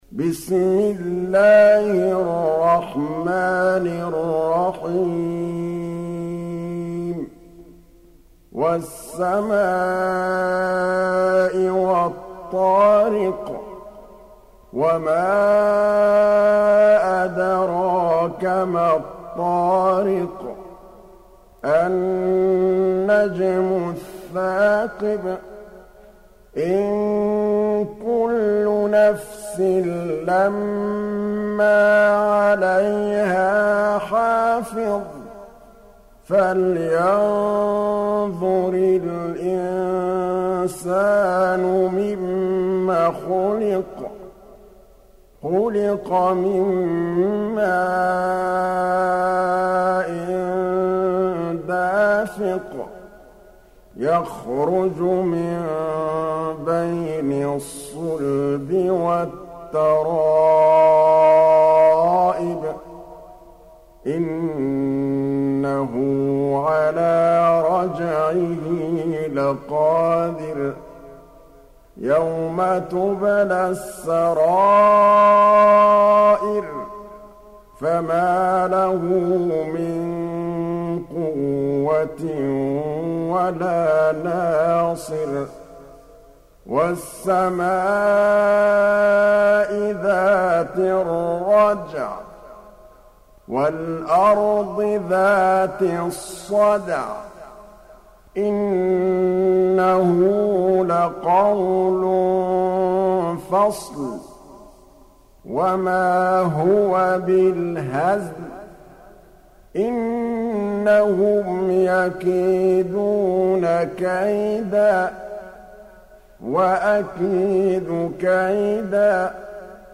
86. Surah At-T�riq سورة الطارق Audio Quran Tarteel Recitation
الشيخ محمد الطبلاوي | حفص عن عاصم Hafs for Assem
Surah Sequence تتابع السورة Download Surah حمّل السورة Reciting Murattalah Audio for 86.